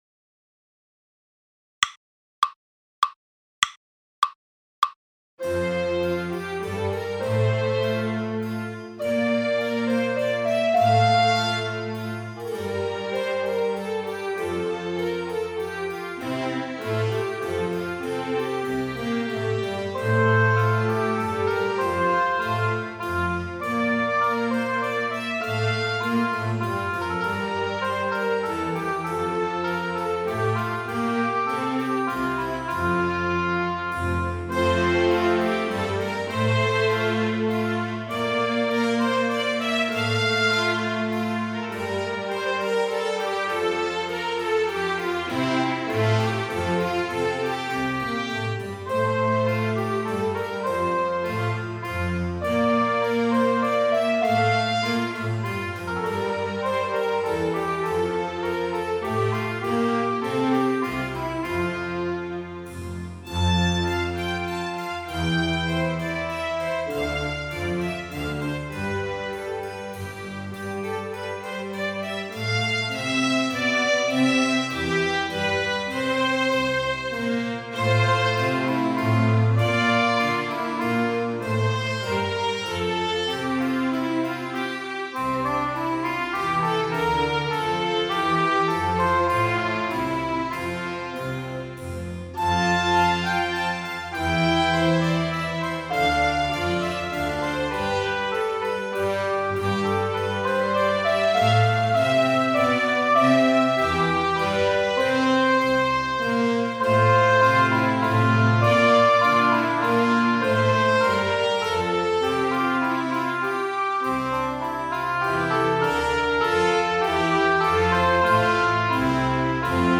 bach_menuet_orquesta_para_danza2.mp3